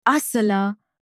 این کلمه دقیقاً معادل فارسی عسل بوده و رایج‌ترین و شناخته‌شده‌ترین واژه برای آن در زبان عربی است. تلفظ: عَسَل (َ`asal) این کلمه در زبان عربی با حرف “ع” آغاز می‌شود که تلفظ آن کمی برای ما فارسی‌زبانان متفاوت است. حرف “ع” حلقی است و از قسمت میانی گلو تلفظ می‌شود.